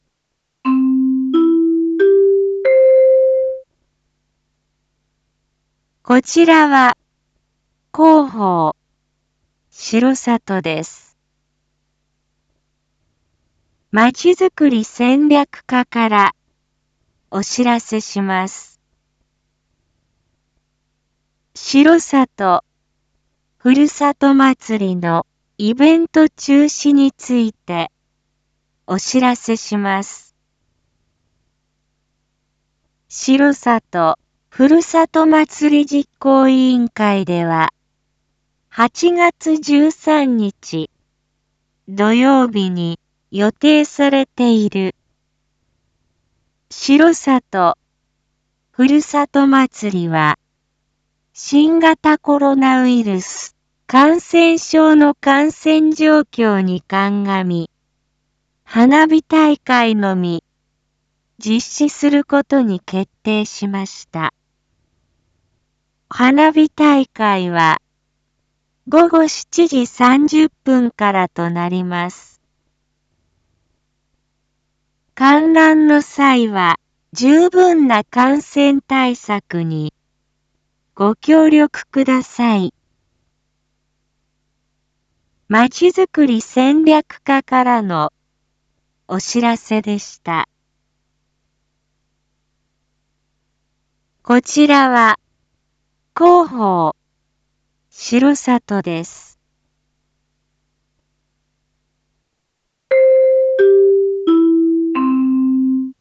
一般放送情報
Back Home 一般放送情報 音声放送 再生 一般放送情報 登録日時：2022-08-08 19:01:47 タイトル：R4.8.8 19時放送分 インフォメーション：こちらは広報しろさとです。